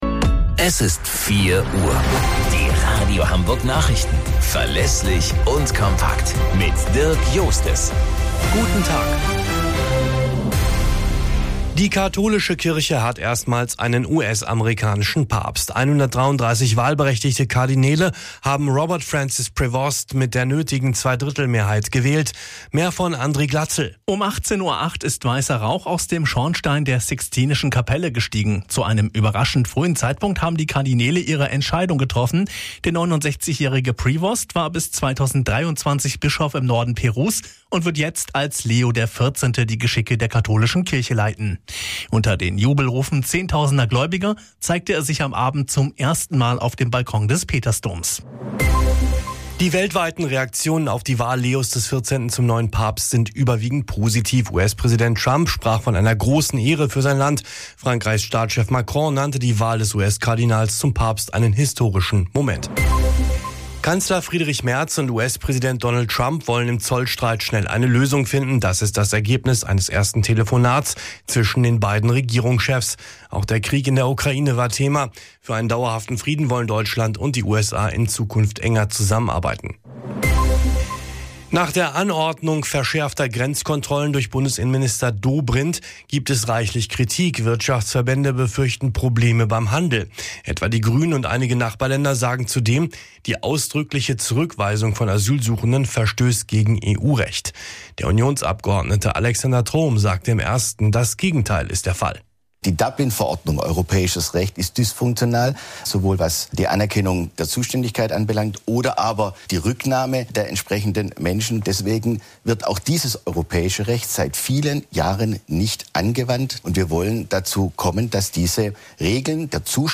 Radio Hamburg Nachrichten vom 09.05.2025 um 04 Uhr - 09.05.2025